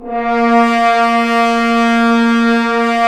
Index of /90_sSampleCDs/Roland L-CD702/VOL-2/BRS_F.Horns 1/BRS_FHns Ambient
BRS F.HRNS0B.wav